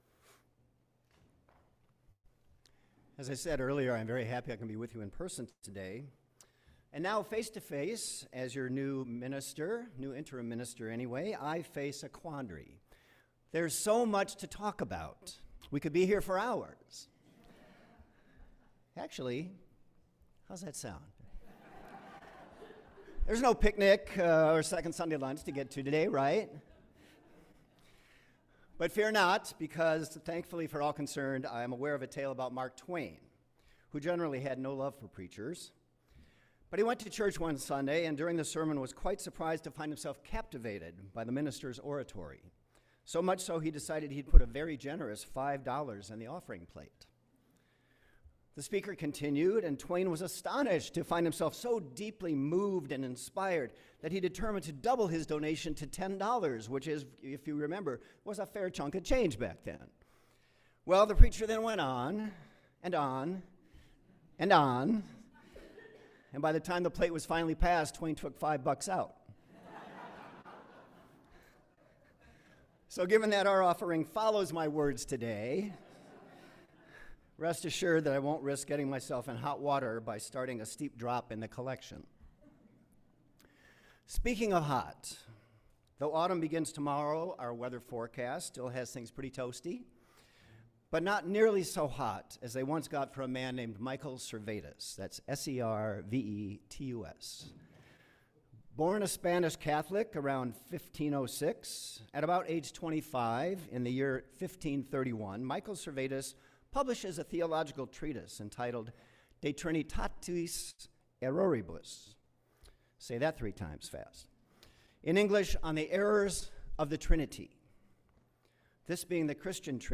Unitarian Universalist Church of Palo Alto Sermons and Reflections – Unitarian Universalist Church of Palo Alto